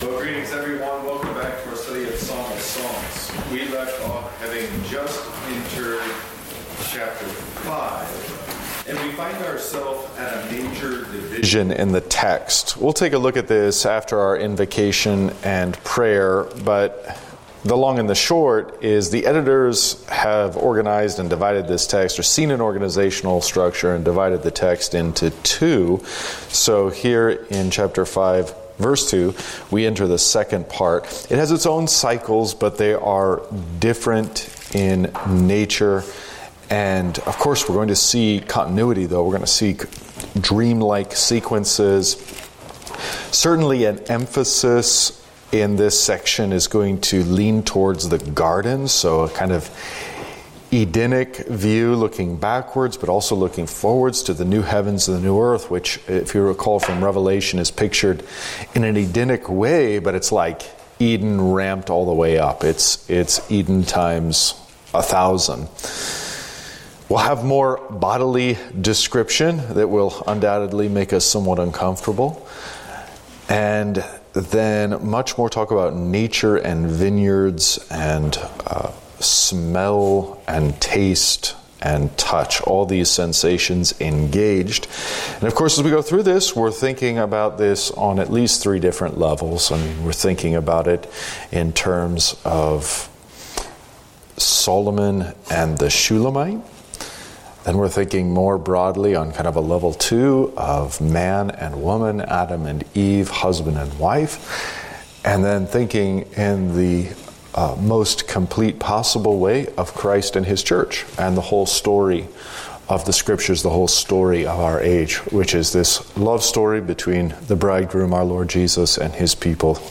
Pastor reviews his teaching on Genesis 15, where God promises Abram that his descendants will be as numerous as the stars, a promise signified by Abram's faith being counted as righteousness.